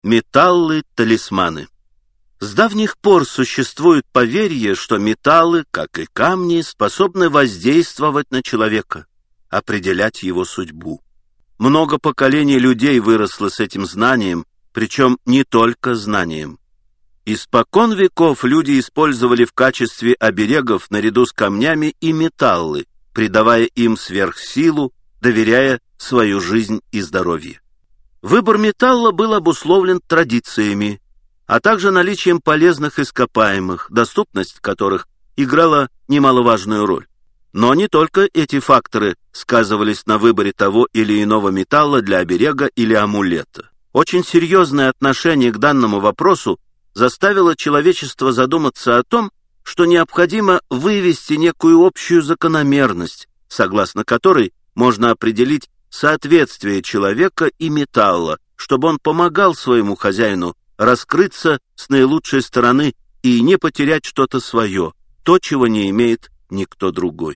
Аудиокнига Гороскоп. Талисманы и обереги. Металлы-талисманы. Благоприятные цвета | Библиотека аудиокниг